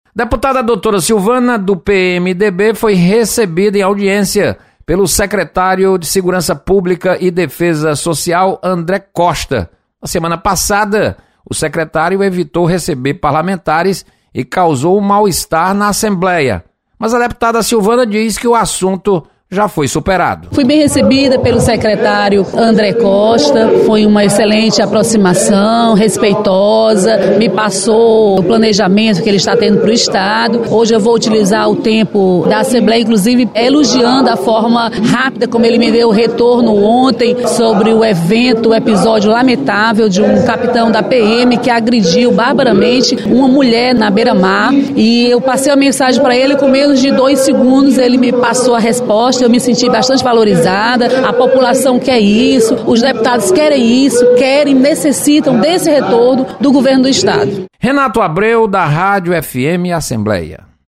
Deputada Doutora Silvana relata visita à Secretaria de Segurança Pública e Defesa Social. Repórter